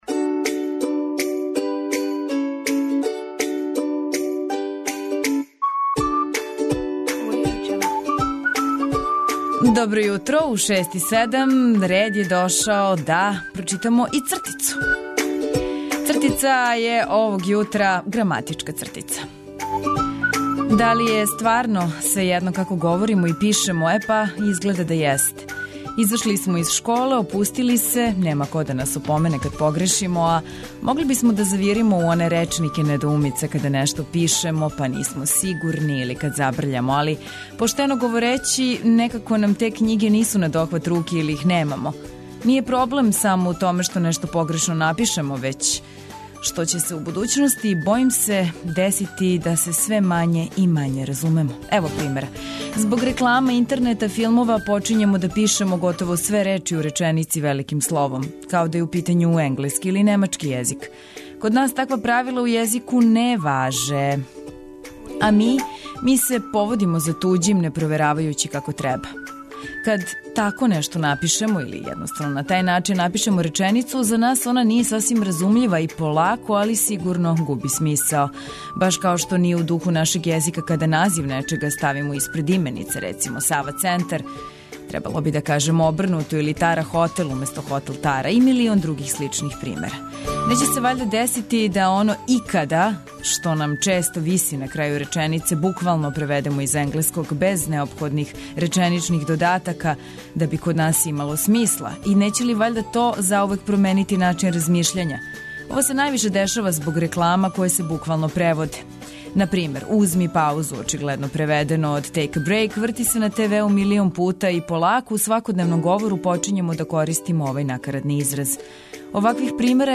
Данас јутро дочекујемо смехом и бројним новостима из света спорта.
Ту смо да вас обавестимо о новостима и сервисним информацијама, размрдамо рано ујутру добром музиком и рубрикама које су типичне за Устанак - "Квака", "Добро јутро" и "1001. траг".